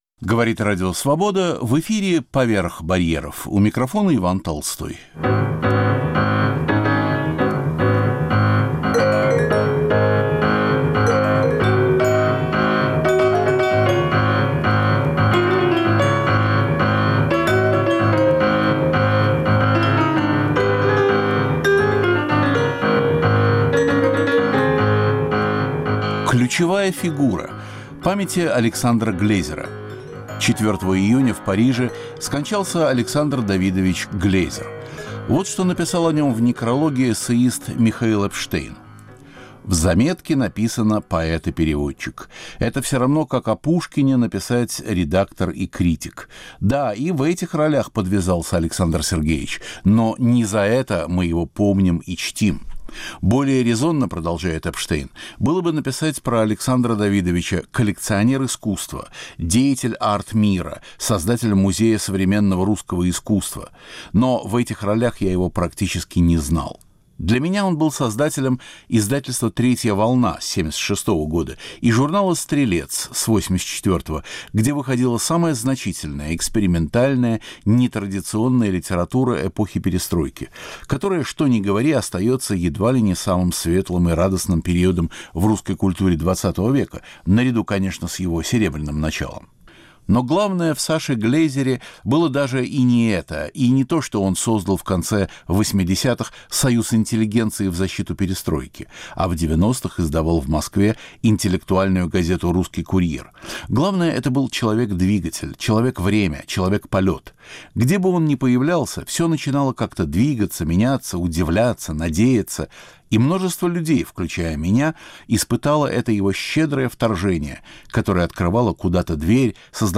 Памяти Александра Глезера – издателя, поэта, создателя нескольких музеев современной российской живописи во Франции и Америке. Архивные записи Свободы.